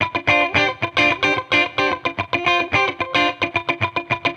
Index of /musicradar/sampled-funk-soul-samples/110bpm/Guitar
SSF_TeleGuitarProc2_110D.wav